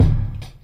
Kick 8.wav